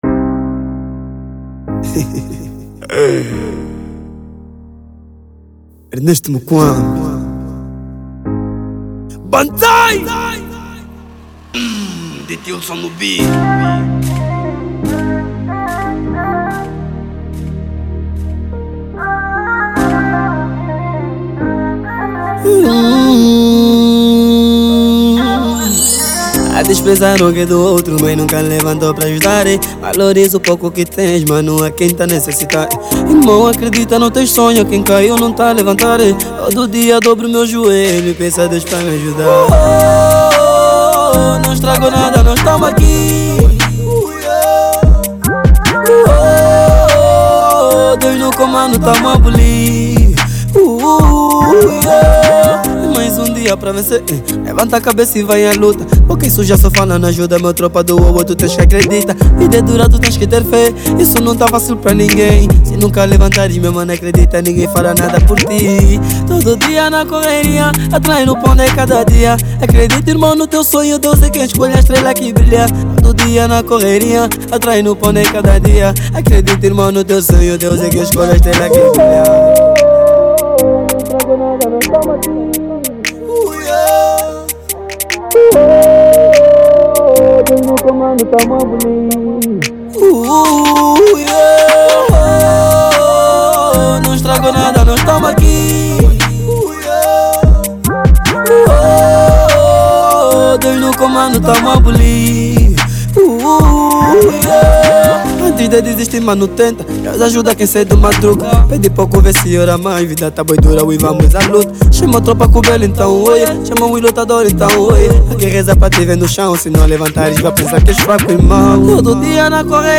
| Afro trap